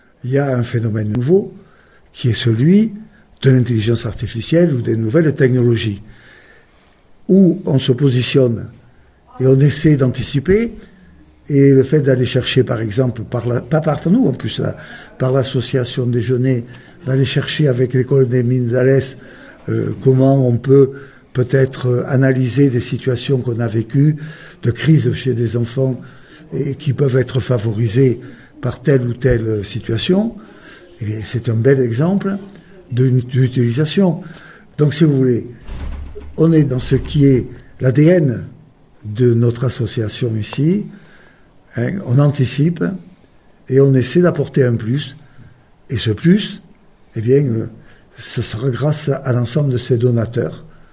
Un nouvel outil de financement pour rester à la pointe de la recherche, explique le docteur Jacques Blanc, président de l’association.